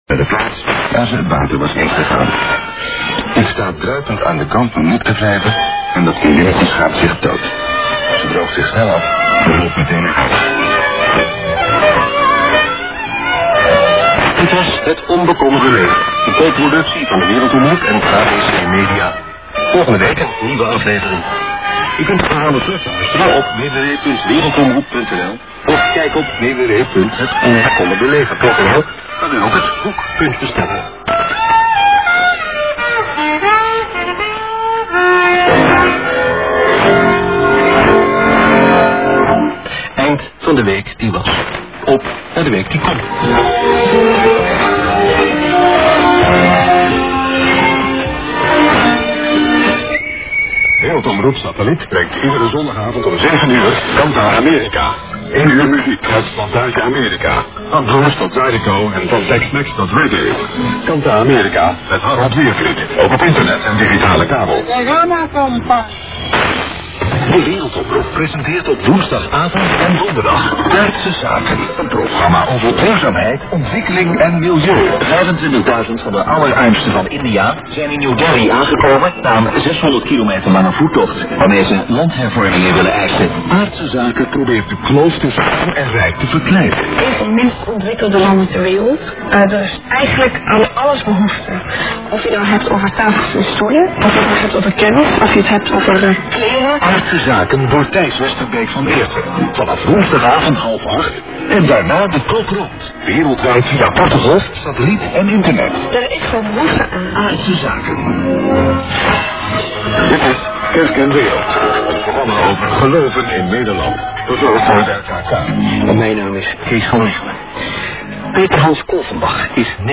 DXPedition Itatiaiuçu-MG JAN-2008
ICOM IC-R75 c/DSP + Ham Radio Deluxe
02 Antenas Super KAZ 90 graus uma da outra NORTE-SUL E LESTE-OESTE